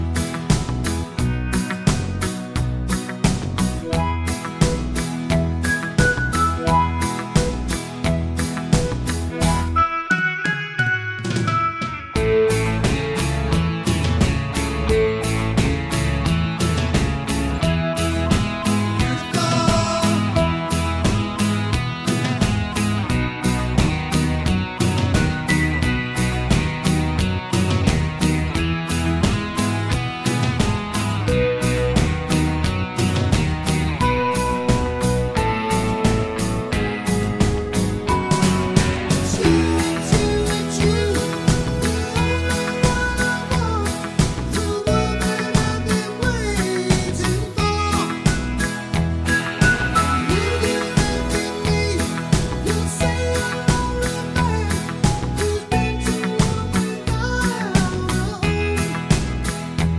no Backing Vocals Irish 3:33 Buy £1.50